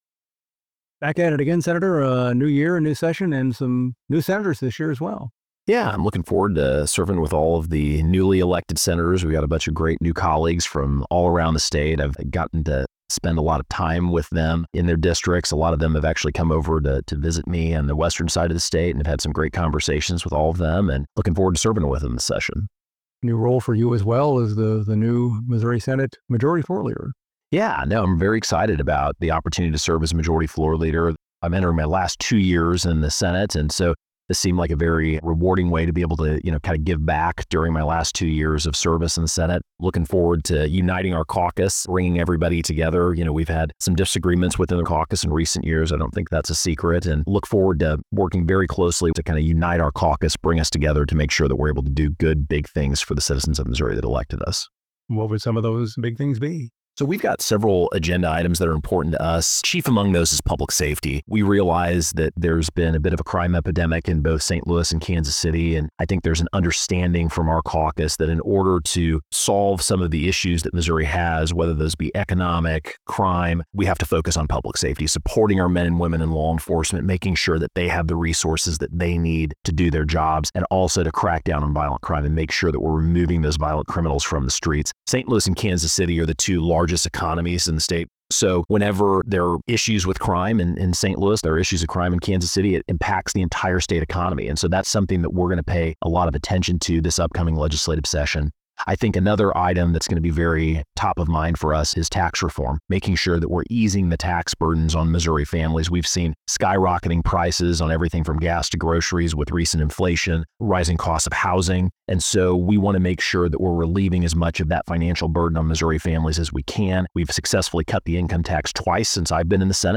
Senator Tony Luetkemeyer, R-Parkville, discusses the start of the 2025 legislative session, his new role as Missouri Senate Majority Floor Leader and some of his legislative priorities for this year.